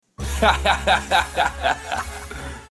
Laugh Ha-Ha